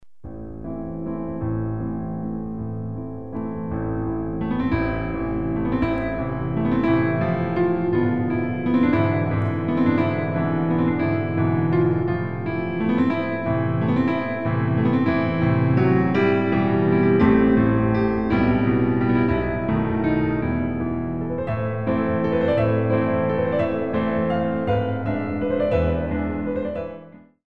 Piano Arrangements of Classical Compositions